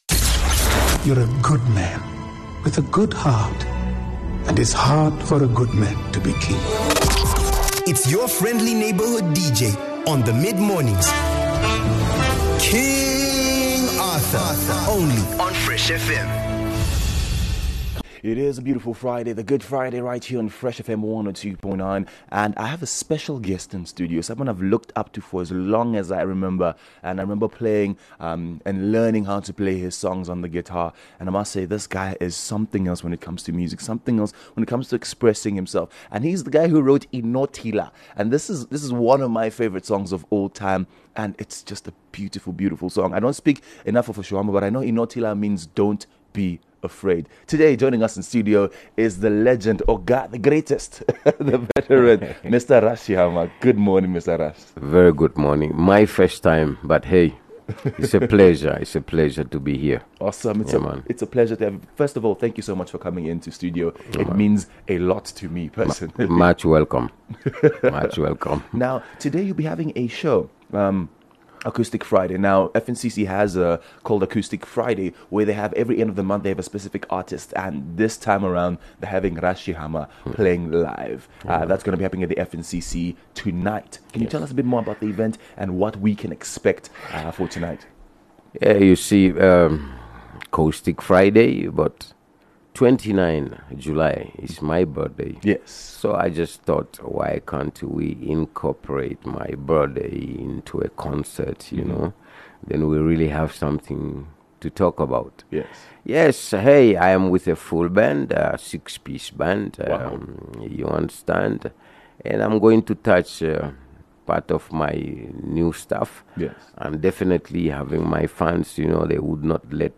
in studio to chat upcoming projects and his musical journey from exile, through independence till now..